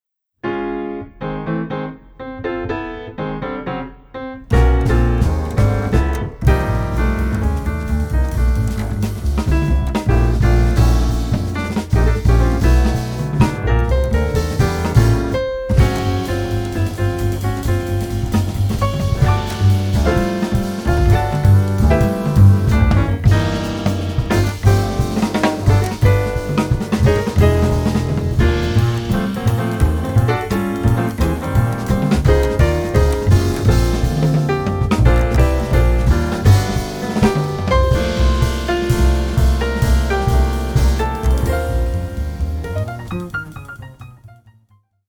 クールで情熱的なオリジナルアレンジがジャズの伝統と現代を融合。
深いグルーヴ、洗練された旋律、躍動感が織りなす極上のサウンド。